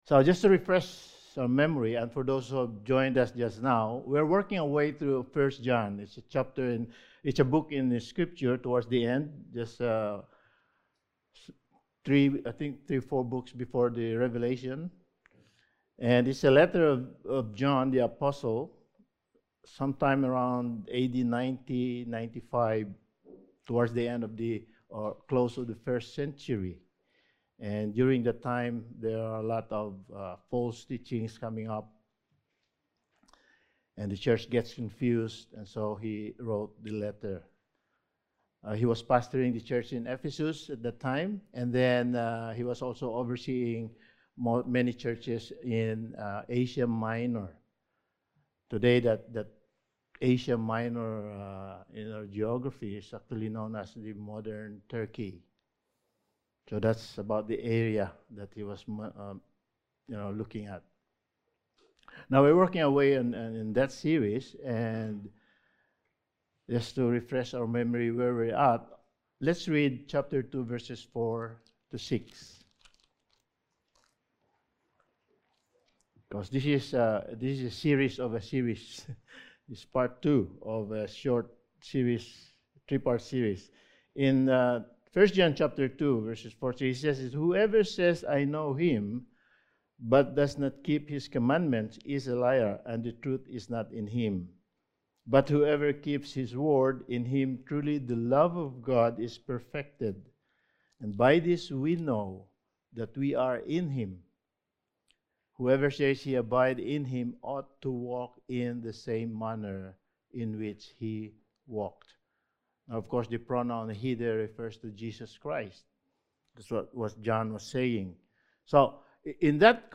1 John Series – Sermon 6: A Biblical View of Love Part 2 – Loving God
Service Type: Sunday Morning